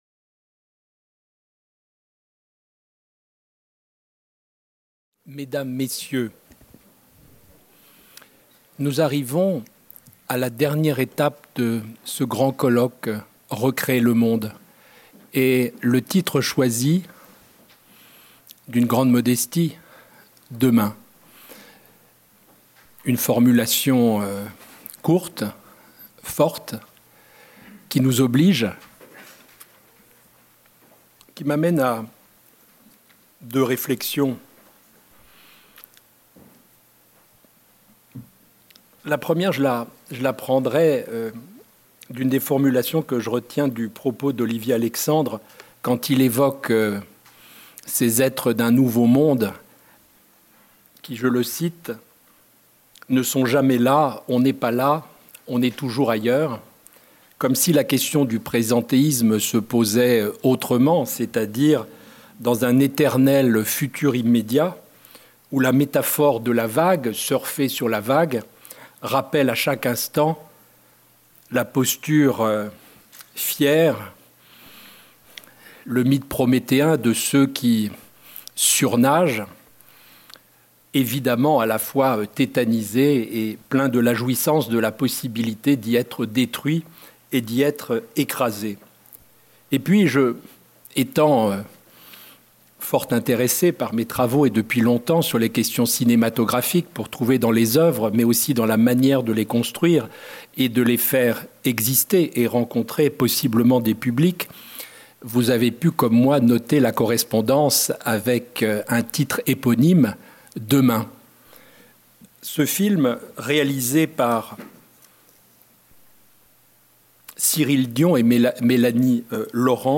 Philharmonie, Salle de conférence Demain